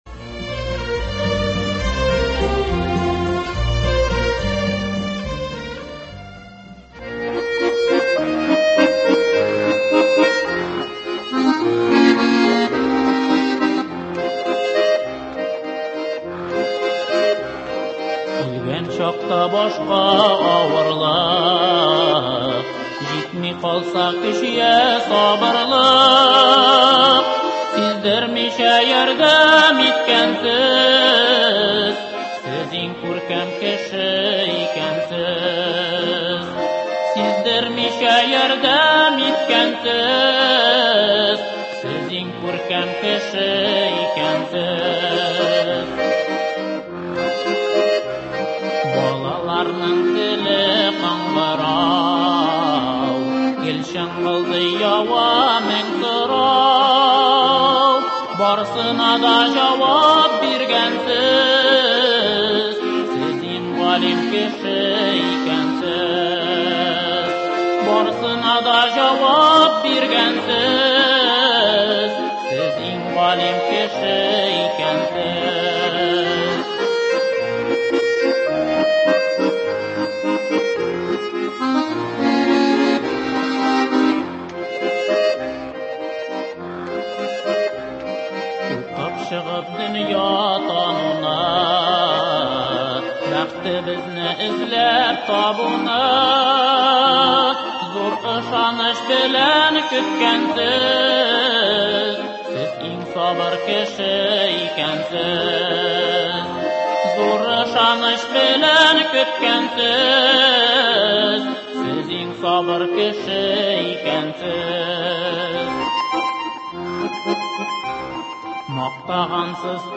Без дә студиябезгә укытучыларның иң-иңнәрен чакырдык.